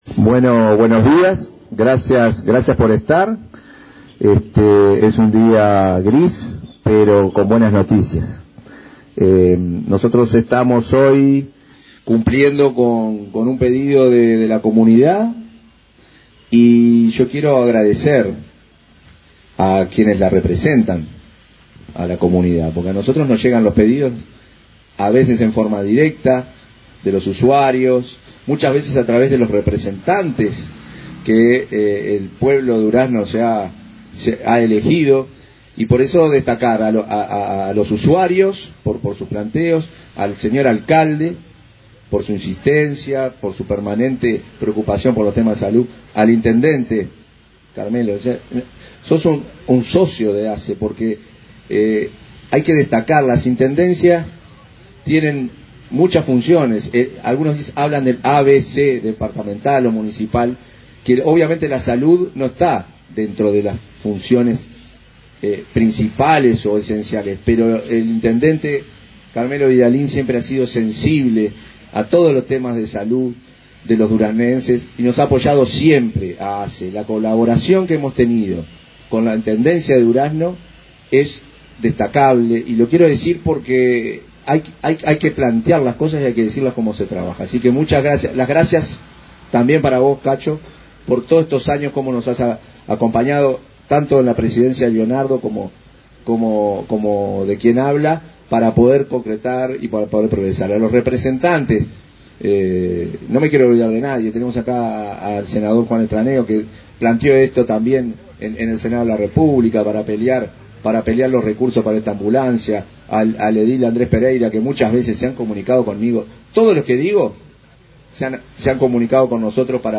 Palabras del presidente de ASSE, Marcelo Sosa
Palabras del presidente de ASSE, Marcelo Sosa 05/06/2024 Compartir Facebook X Copiar enlace WhatsApp LinkedIn La Administración de los Servicios de Salud del Estado (ASSE) entregó, este 5 de junio, una ambulancia para policlínica de Villa del Carmen, en Durazno. En el evento participó el presidente de ASSE, Marcelo Sosa.